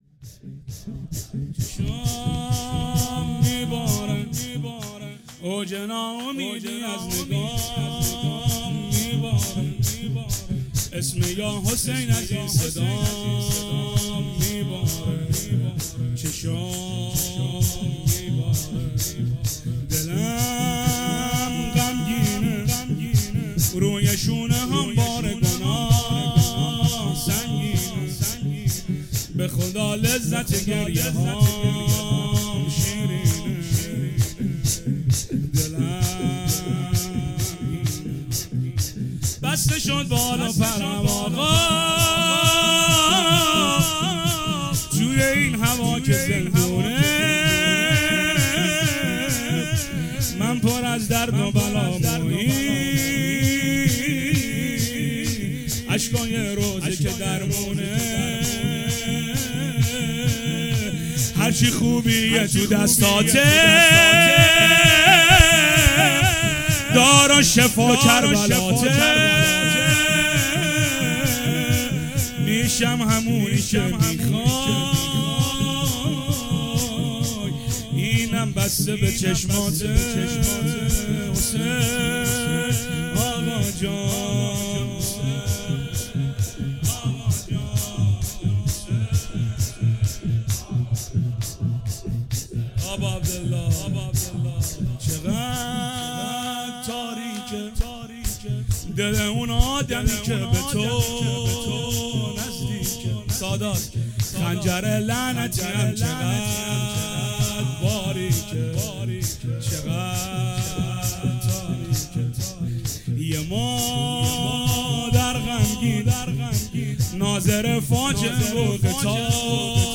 شور | چشام میباره
شب سوم فاطمیه اول ۱۴۰۱